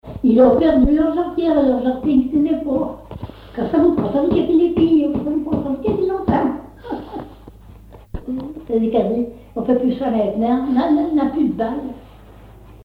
Localisation Cancale (Plus d'informations sur Wikipedia)
Thème : 1074 - Chants brefs - A danser
Fonction d'après l'analyste danse : gigouillette ;
Genre brève
Catégorie Pièce musicale inédite